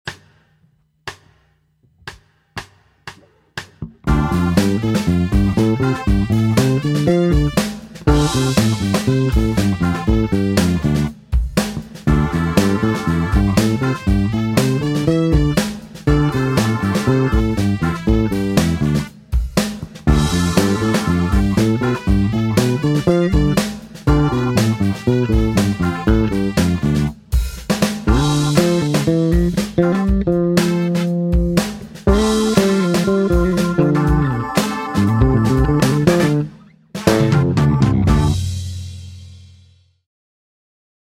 3 Escalas Pentatonicas para D